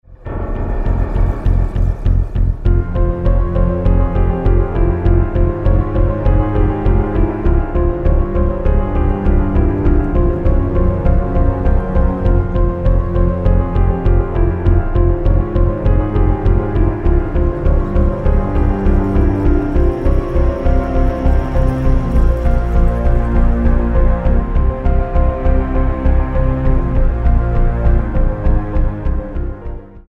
The ULTIMATE haunted house CD!